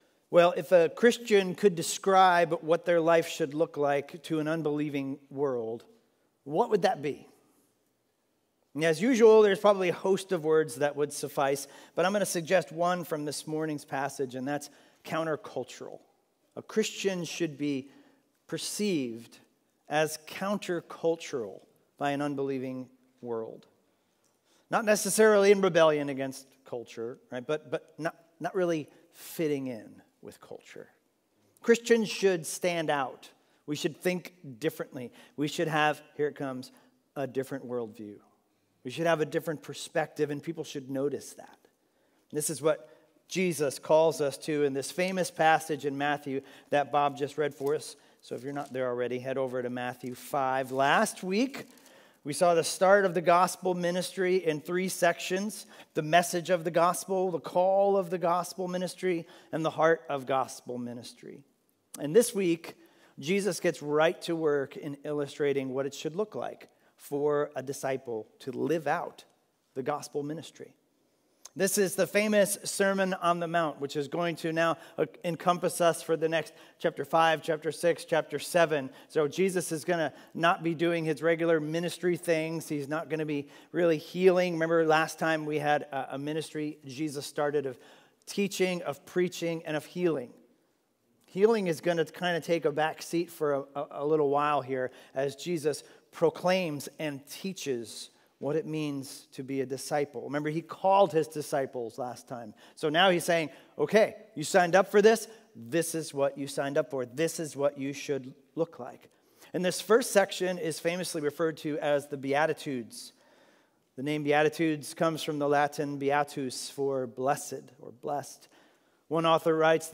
Expositional teaching series through the book of Matthew - starting Sunday, Dec 6, 2020